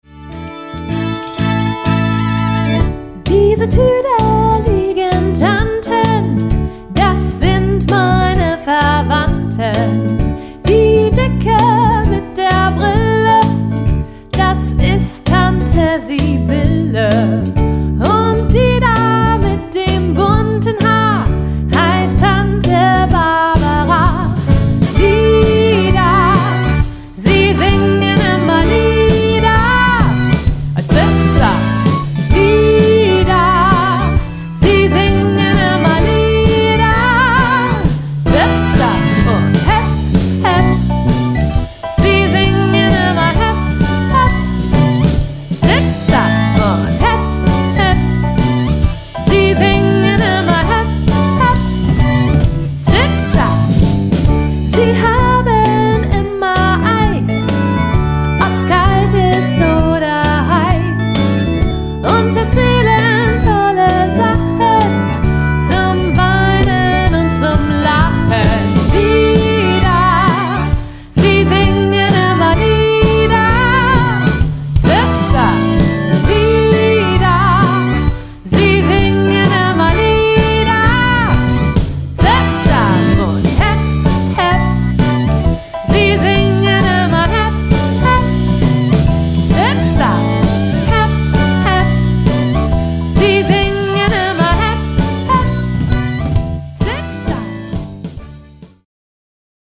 Tuedelige_tanten-probe-Mai-2018.mp3